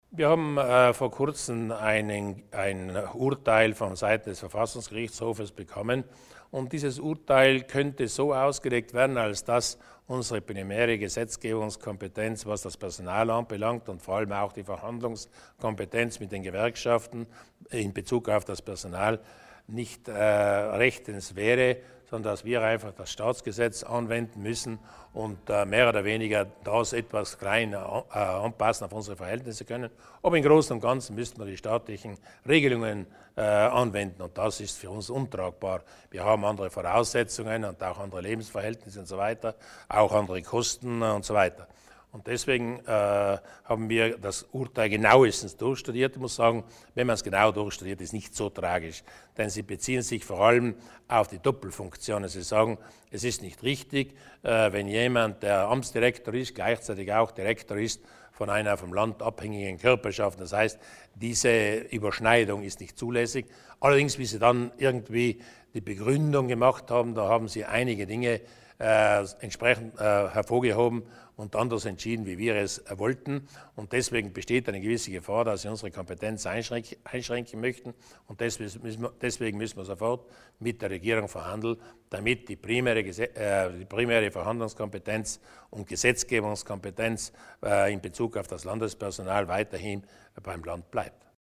Landeshauptmann Durnwalder über die Sorgen bezüglich der Kollektivverträge